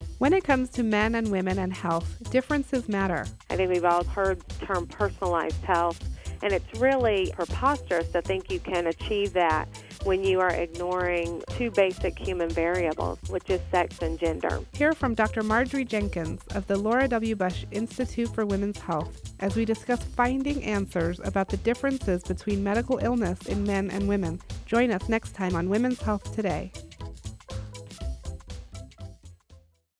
February 8th Women’s Health Today promo